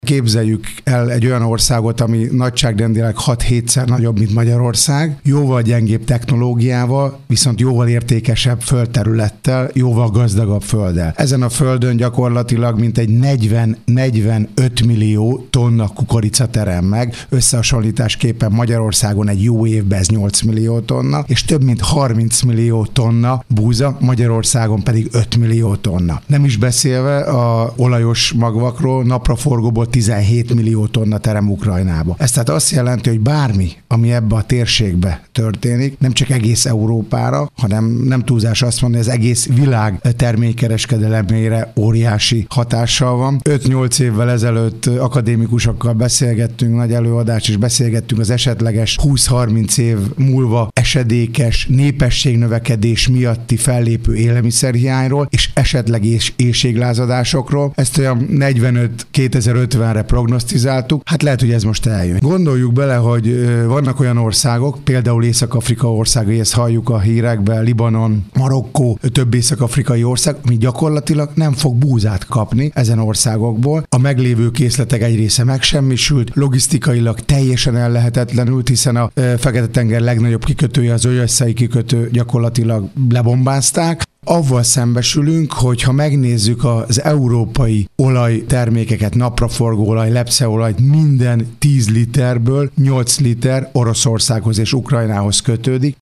Fazekas László polgármester arról beszélt, hogy a gyermeklétszám miatt már feszültség van a bölcsődében, ami megjelenik már az óvodában, néhány év múlva pedig az iskolában is realizálódik.